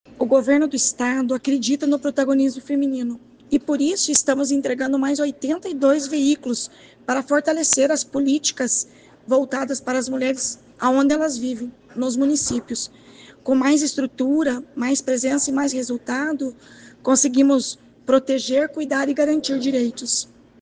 Sonora da secretária da Mulher, Igualdade Racial e Pessoa Idosa, Leandre Dal Ponte, sobre os novos veículos para fortalecer as políticas para mulheres